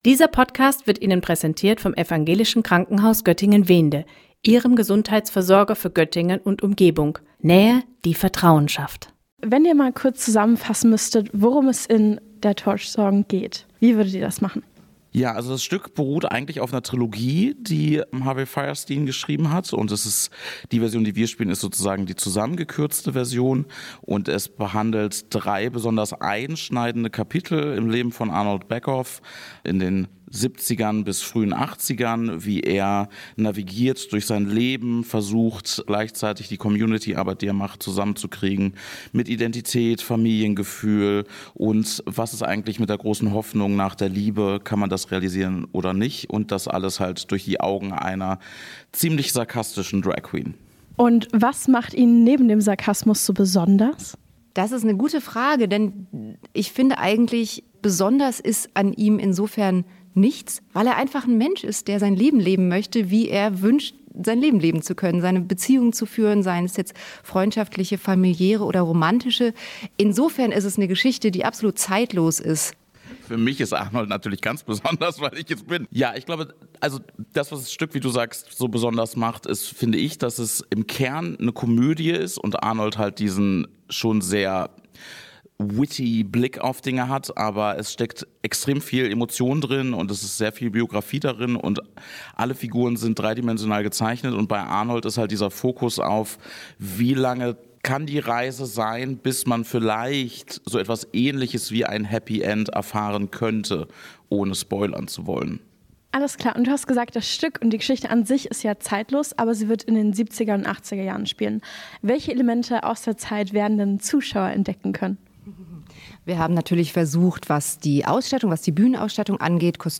Beiträge > Scharfsinnig schimmernd - Interview zu "Torch Song" im Theater im OP - StadtRadio Göttingen